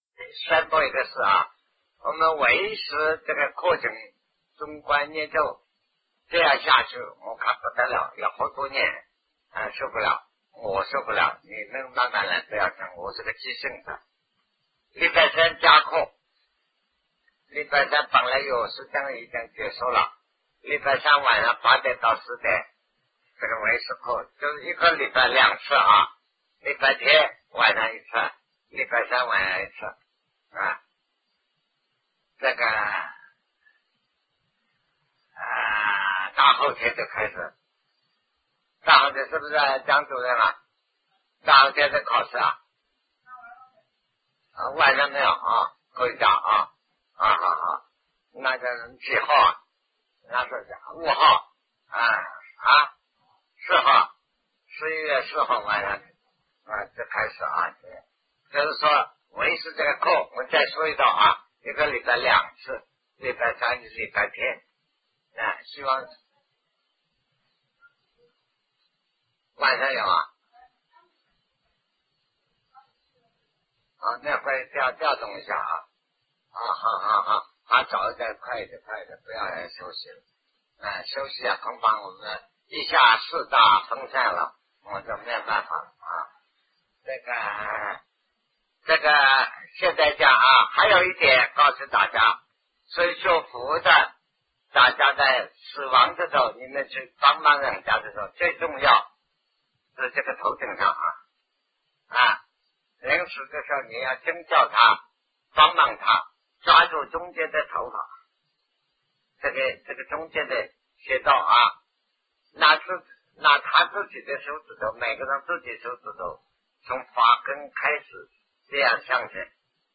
人死之后的情形 南师讲唯识与中观（1980代初于台湾012(上)